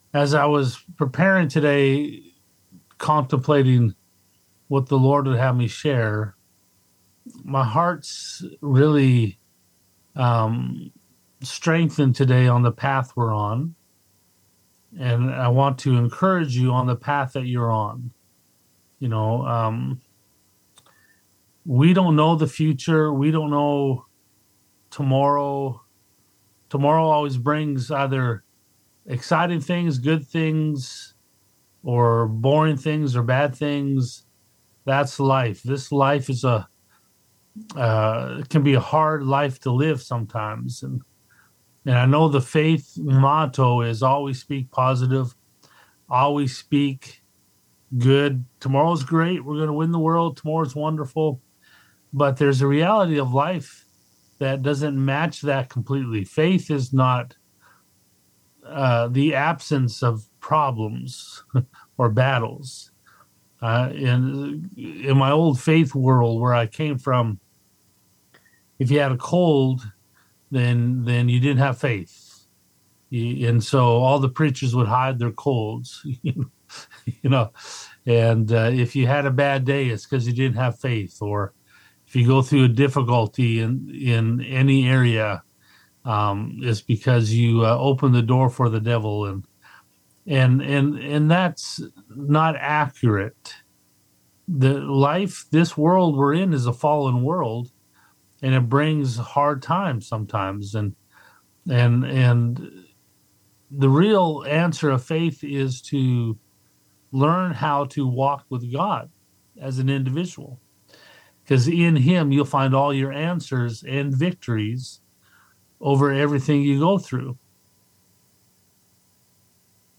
Weekly Sermons - Family Prayer Centre